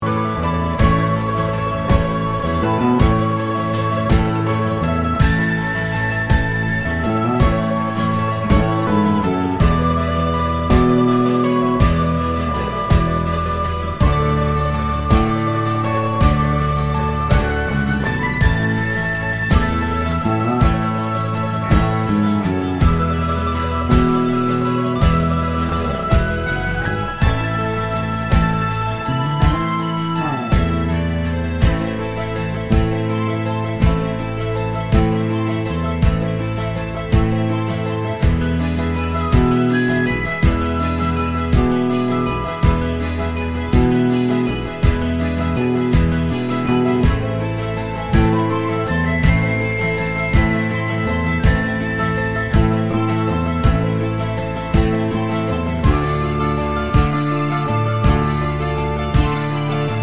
no Backing Vocals Irish 3:48 Buy £1.50
Irish Backing Tracks for St Patrick's Day